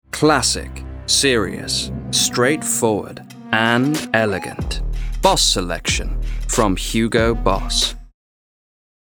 • Male
Commercial - Hugo Boss. Serious, Direct
4.-Aftershave-commercial.mp3